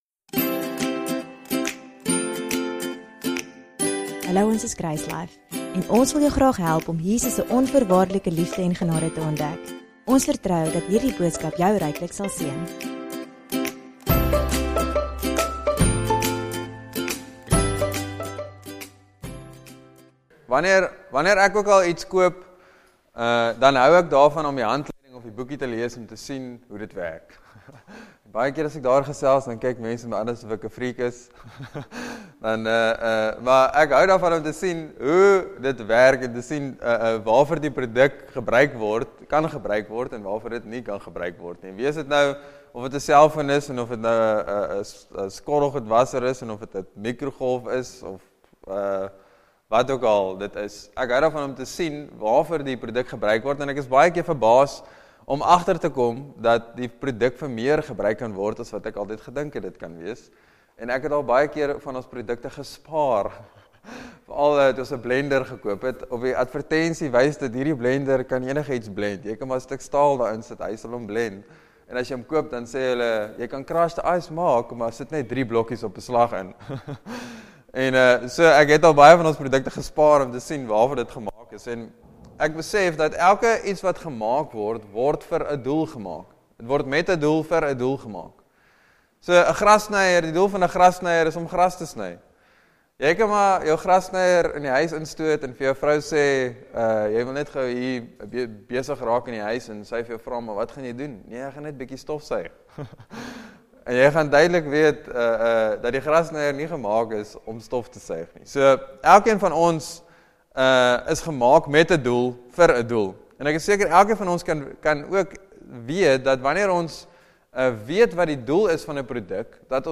DOWNLOAD READ MORE Sermon Test Category